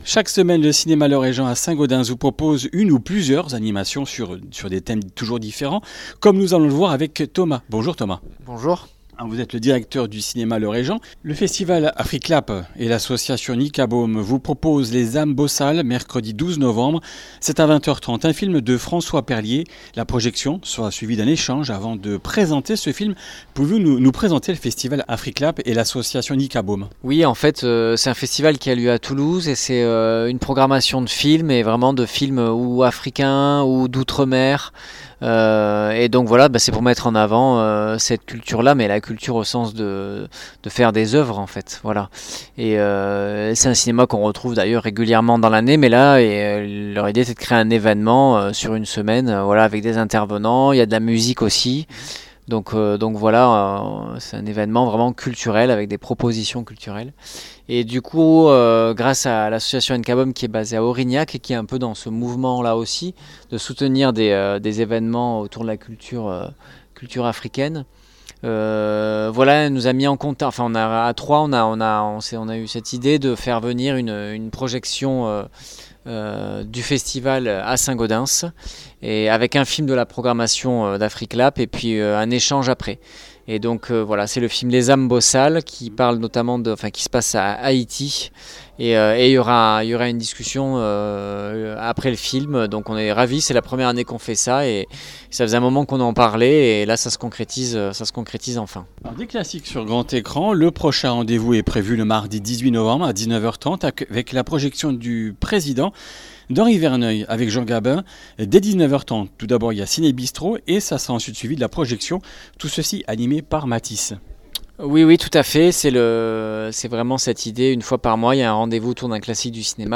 Comminges Interviews du 06 nov.